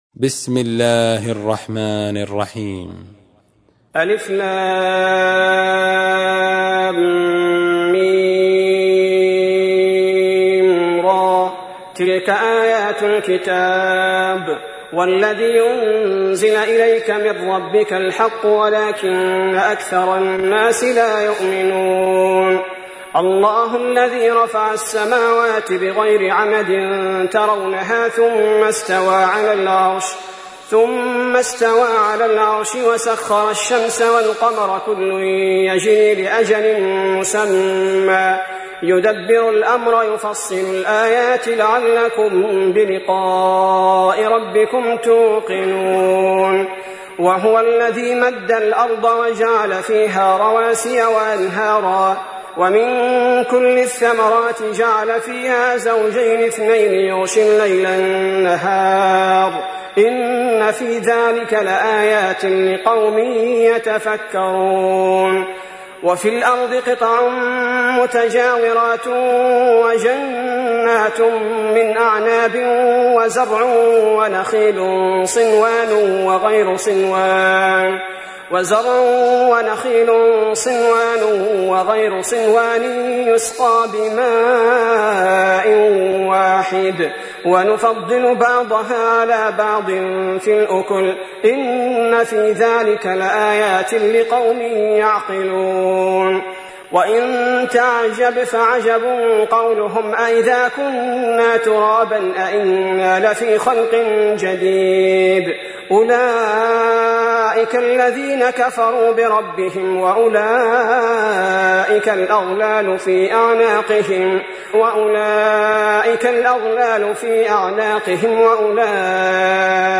تحميل : 13. سورة الرعد / القارئ عبد البارئ الثبيتي / القرآن الكريم / موقع يا حسين